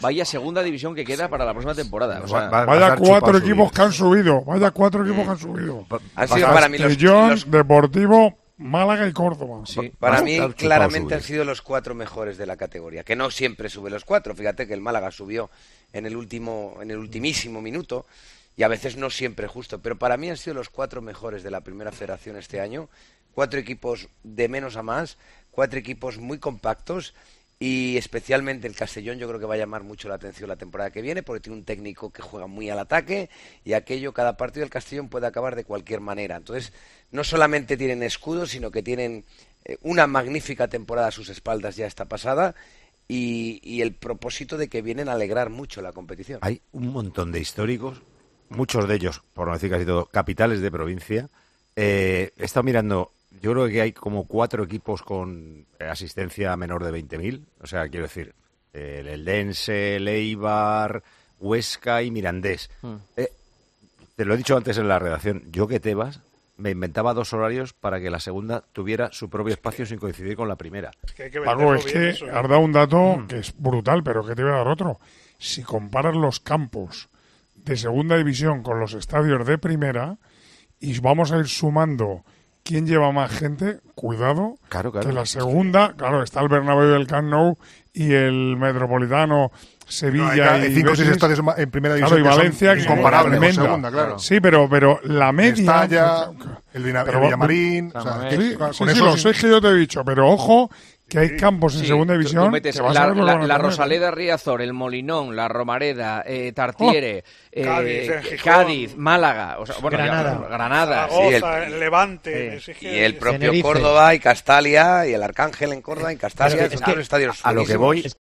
Durante el Tertulión de Tiempo de Juego, Manolo Lama, Paco González, Juanma Castaño y Santi Cañizares se 'relamían' pensando en el potencial de la Segunda División que nos aguarda la temporada que viene.